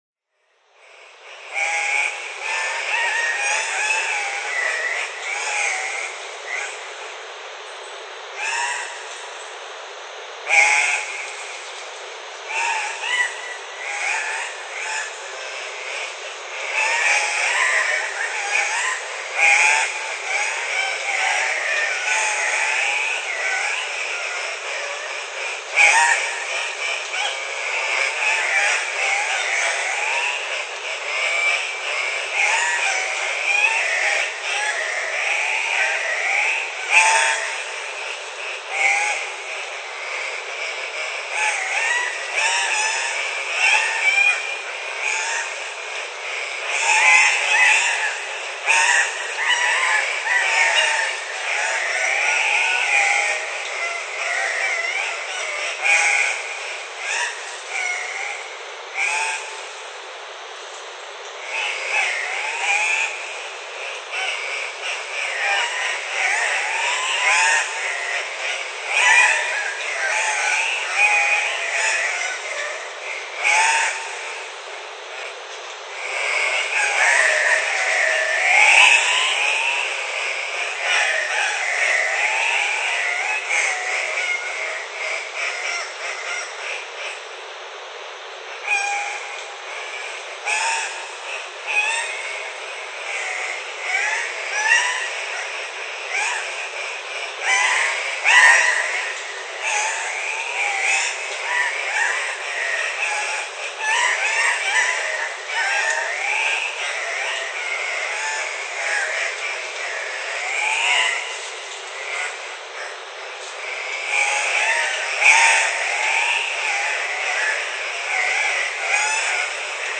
Cocks of the Rock performing courtship display.